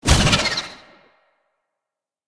Sfx_creature_arcticray_flinch_01.ogg